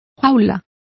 Complete with pronunciation of the translation of crate.